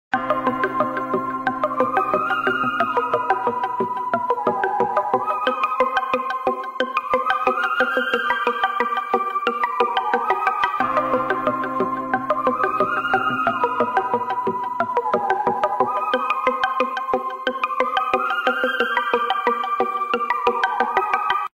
Scared - Horror